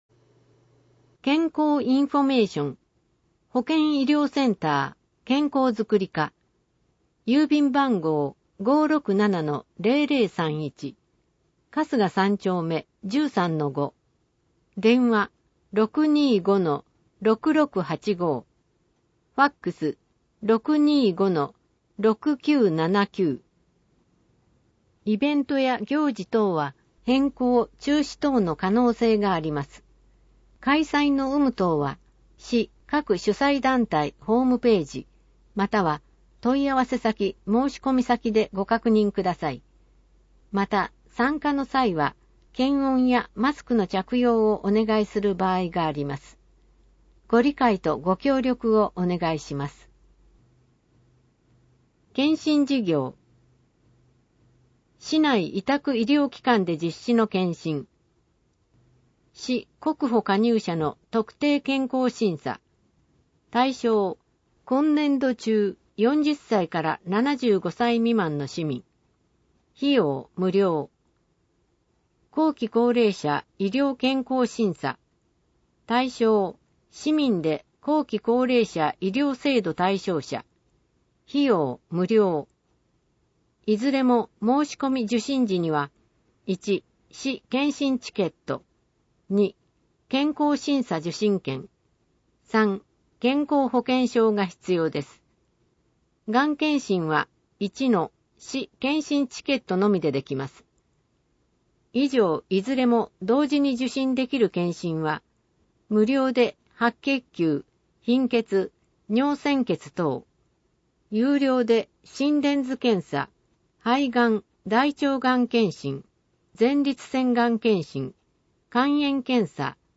毎月1日発行の広報いばらきの内容を音声で収録した「声の広報いばらき」を聞くことができます。